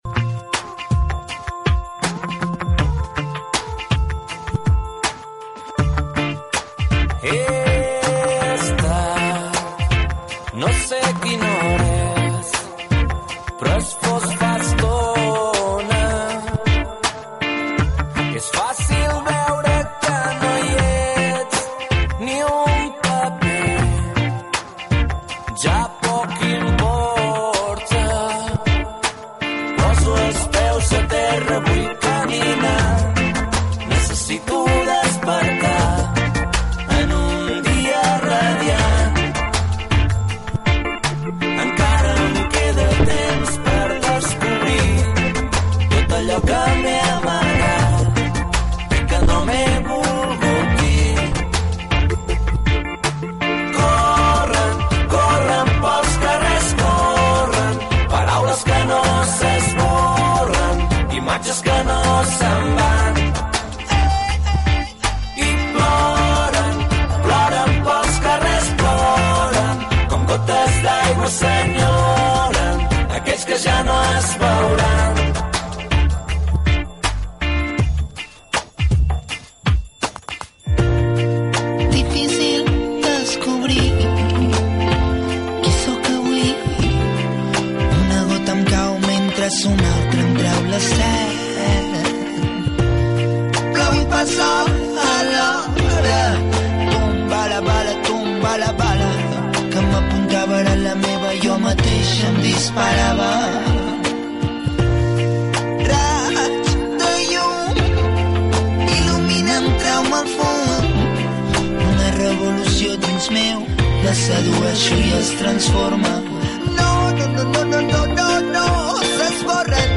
Gènere radiofònic Participació Data emissió 2009-07-07 Banda FM Localitat Masquefa Comarca Anoia Durada enregistrament 10:25 Idioma Català/Castellà Notes Extret del canal d'iVoox de Ràdio Masquefa.